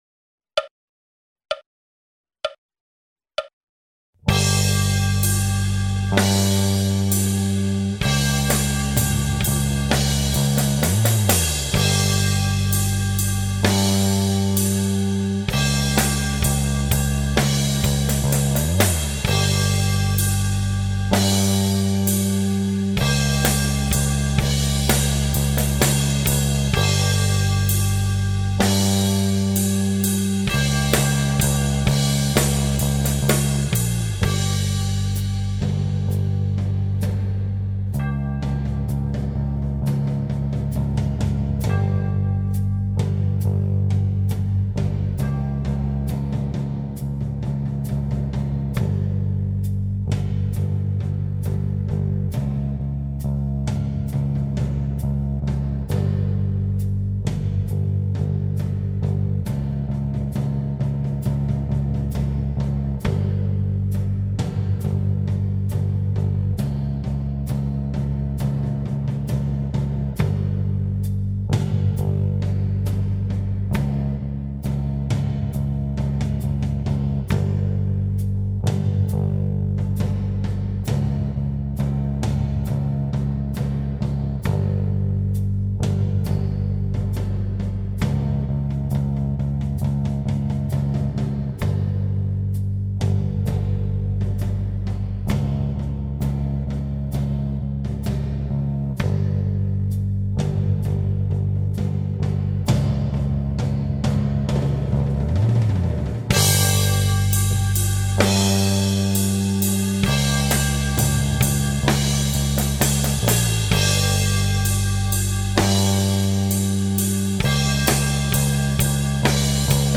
Preset for Fractal Axe FX II: Preset 7 or Preset 9